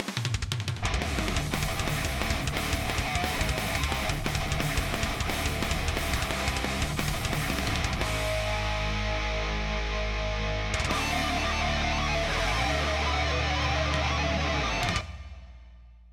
Ripped from game
Fair use music sample